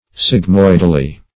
sigmoidally - definition of sigmoidally - synonyms, pronunciation, spelling from Free Dictionary Search Result for " sigmoidally" : The Collaborative International Dictionary of English v.0.48: Sigmoidally \Sig*moid"al*ly\, adv.
sigmoidally.mp3